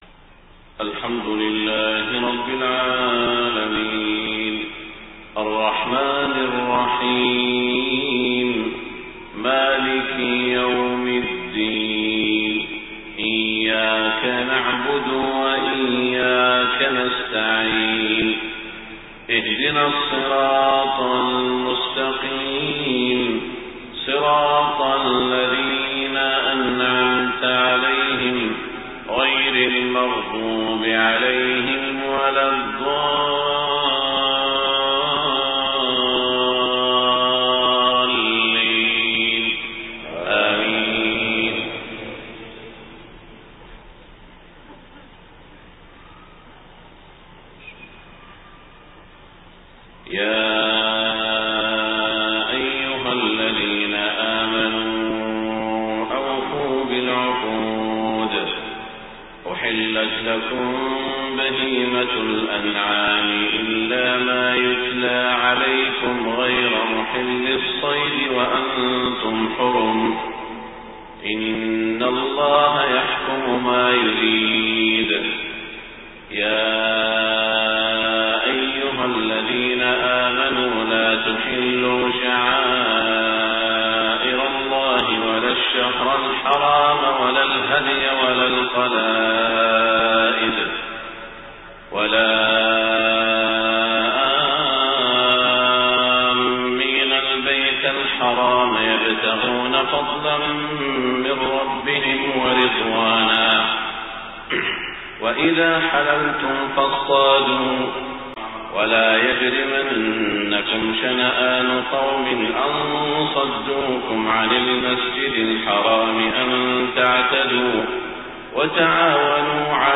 صلاة الفجر 4-3-1429هـ من سورة المائدة > 1429 🕋 > الفروض - تلاوات الحرمين